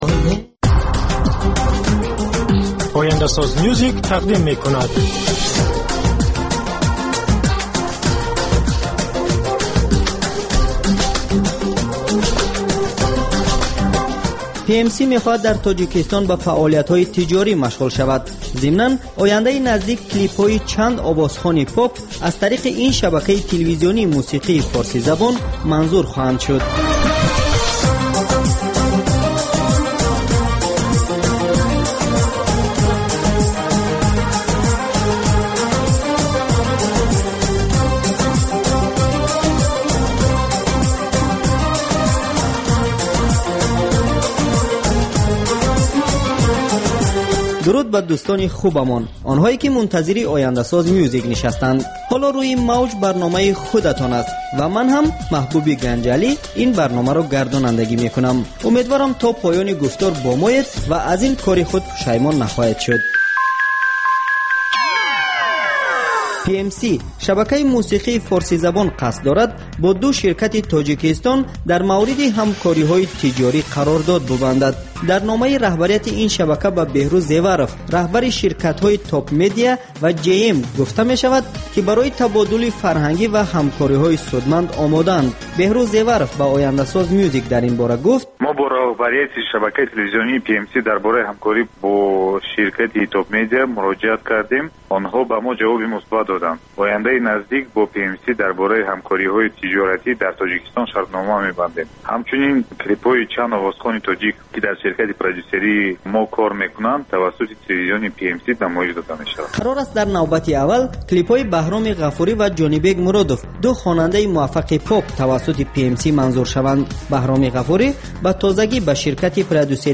Барномаи мусиқӣ